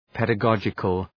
Shkrimi fonetik{,pedə’gɒdʒıkəl}